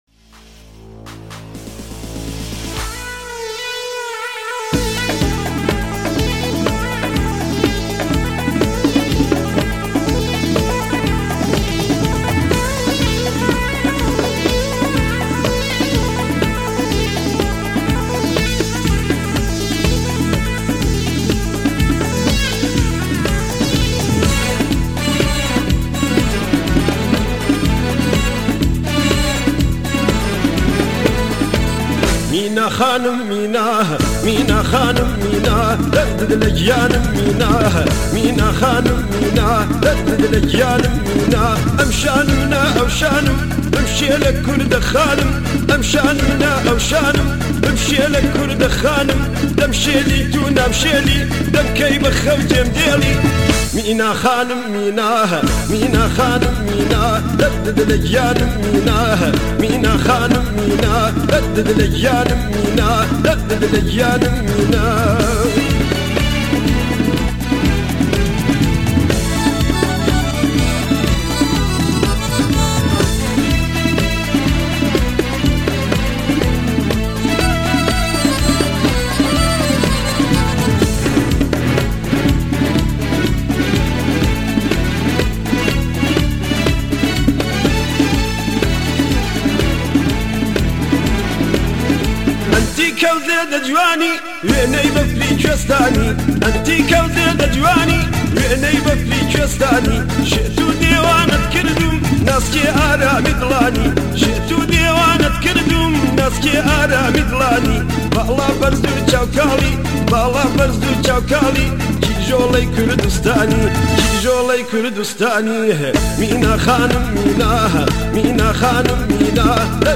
آهنگ کردی فولکلور